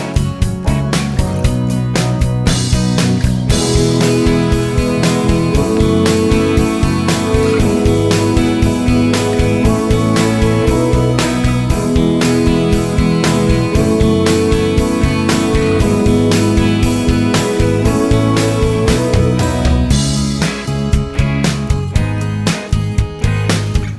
Professional Backing Tracks